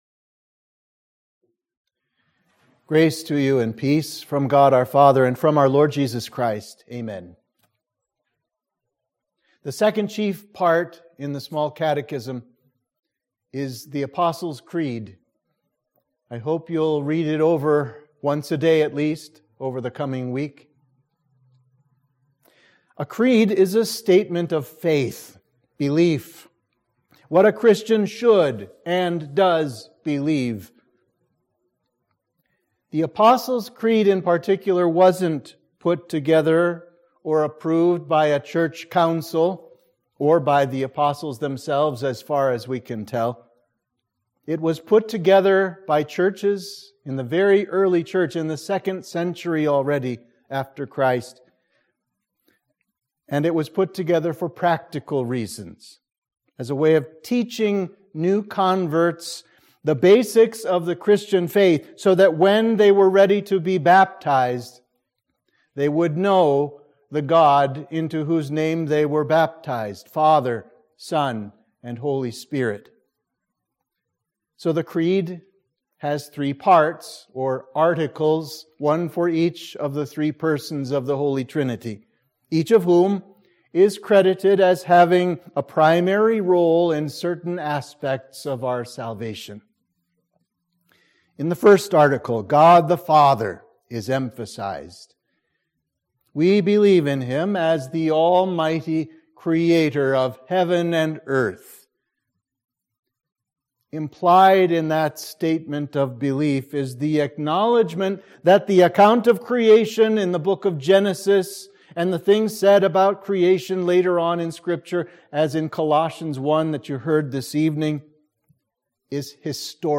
Sermon on the 2nd Chief Part of the Small Catechism